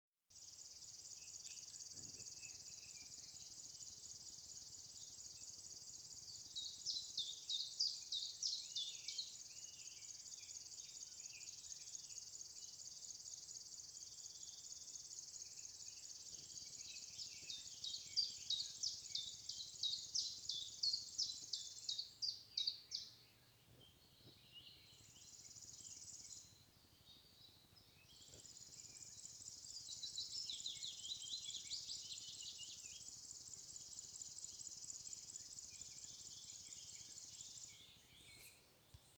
River Warbler, Locustella fluviatilis
StatusSinging male in breeding season